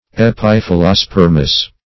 Search Result for " epiphyllospermous" : The Collaborative International Dictionary of English v.0.48: Epiphyllospermous \Ep`i*phyl`lo*sper"mous\, a. [Gr.
epiphyllospermous.mp3